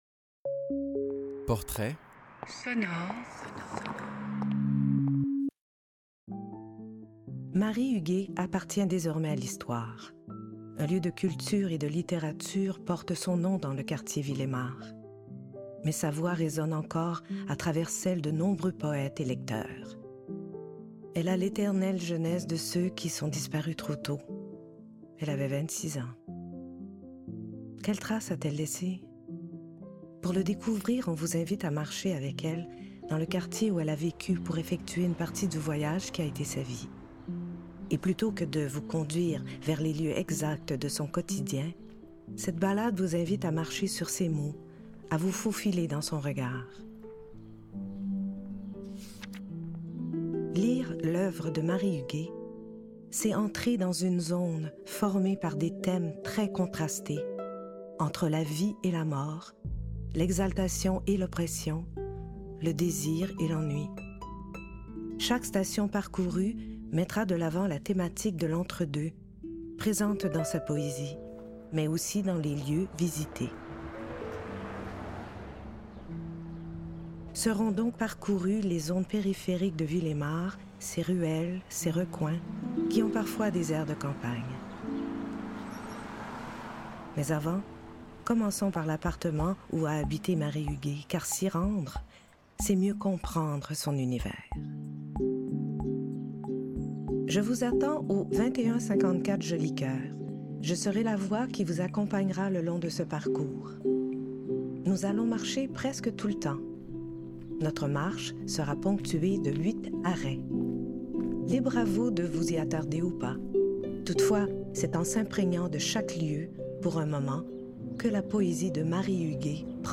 Introduction du portrait sonore]